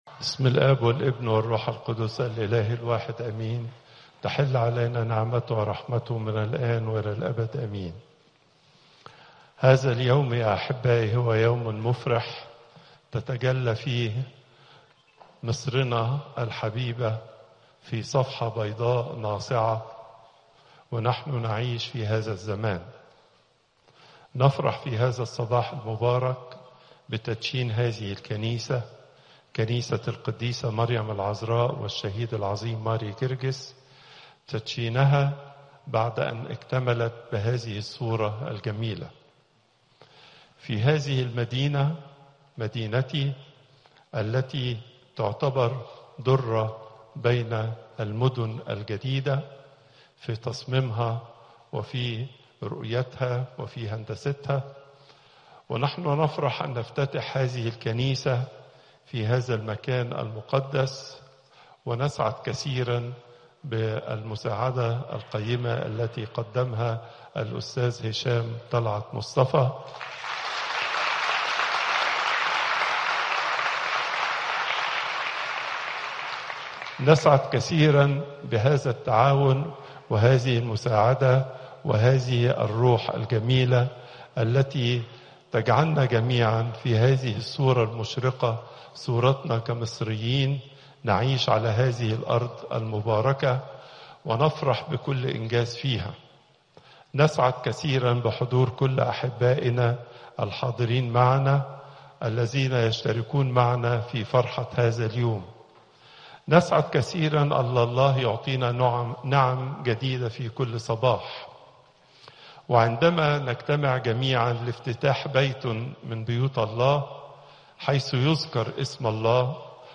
Popup Player Download Audio Pope Twadros II Saturday, 08 February 2025 20:46 Pope Tawdroes II Weekly Lecture Hits: 147